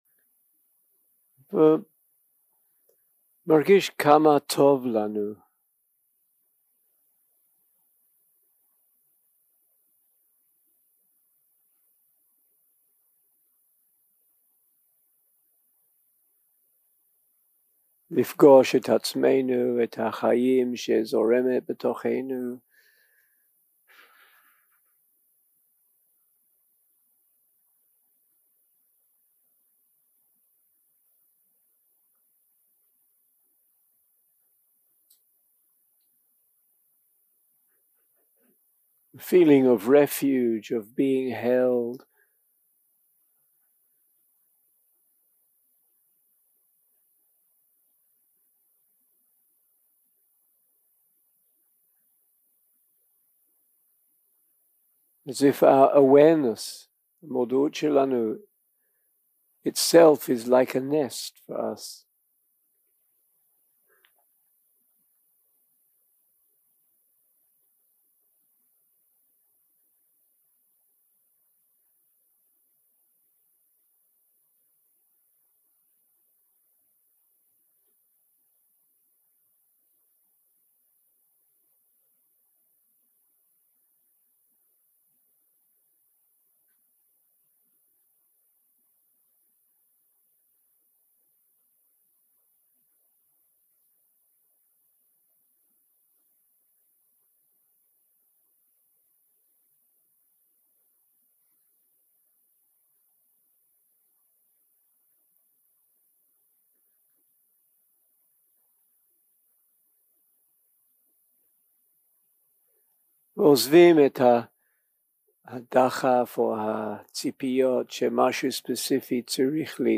יום 3 – הקלטה 5 – צהריים – מדיטציה מונחית
סוג ההקלטה: מדיטציה מונחית